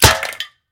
Kick_Can_FORWARD.mp3